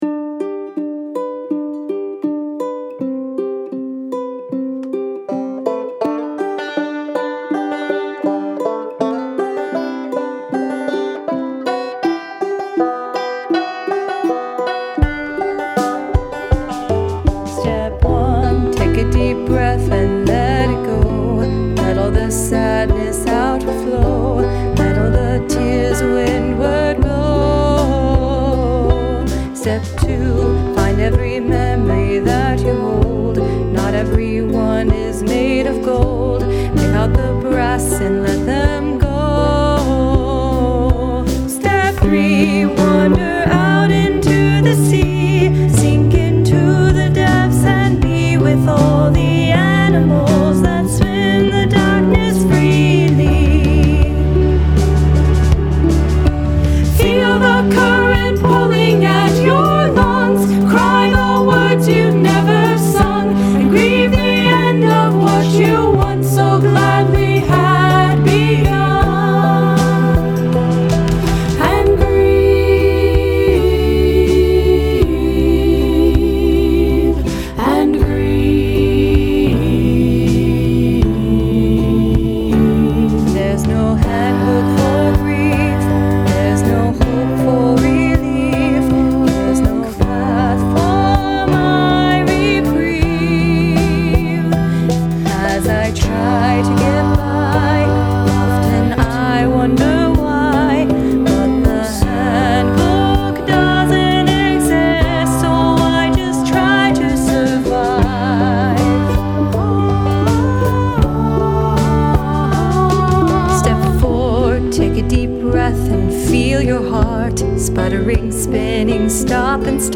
Lead Vocal, Tenor Uke
Banjo
Backing Vocal, Piano, Bass
Drums: GarageBand